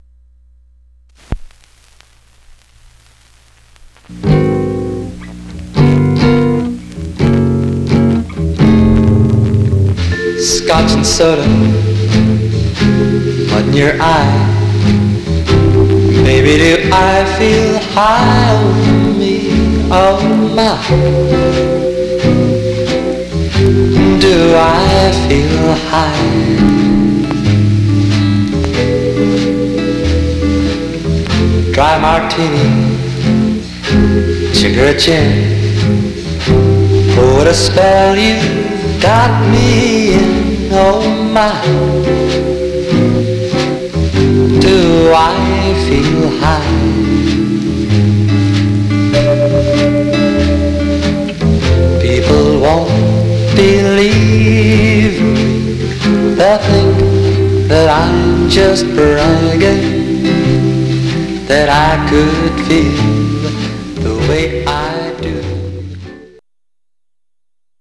Stereo/mono Mono Condition Some surface noise/wear
Jazz